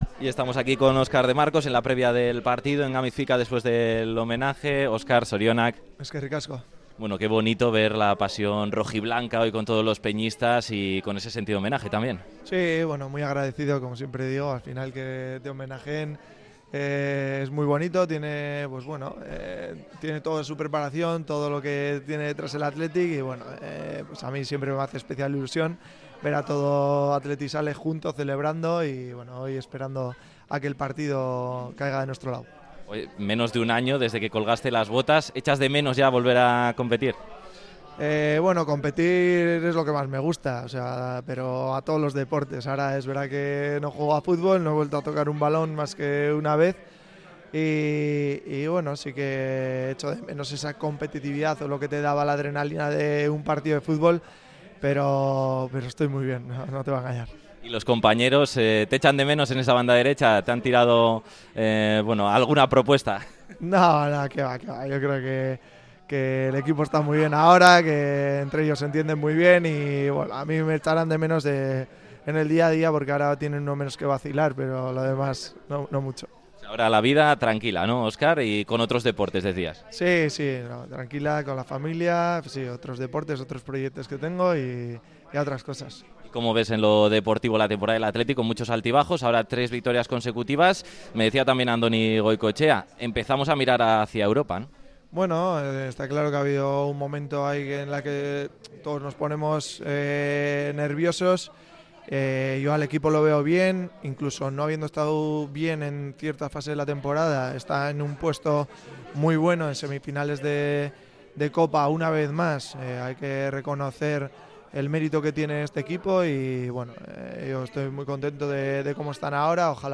Este sábado se ha celebrado el tradicional hermanamiento de Peñas del Athletic en Gamiz-Fika.
El ‘Gudari’, que colgó las botas el pasado verano, ha atendido al micrófono de Radio Popular-Herri Irratia para compartir sus sensaciones en la previa del partido frente al Rayo Vallecano.